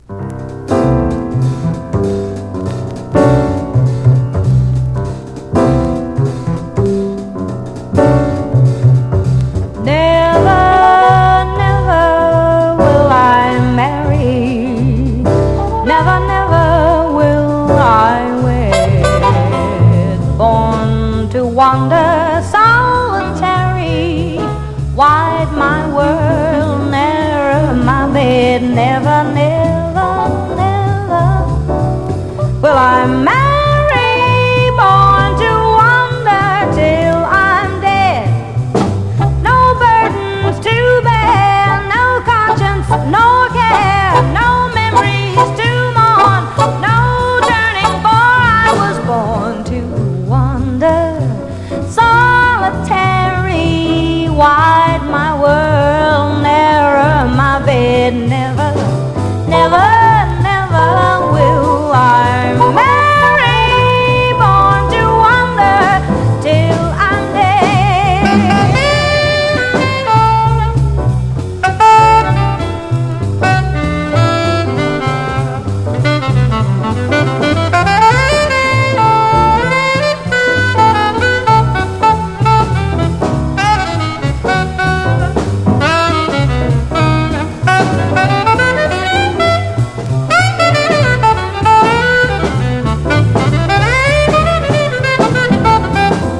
全編でコーラスをフィーチャーしたスピリチュアル・ジャズ・クラシック！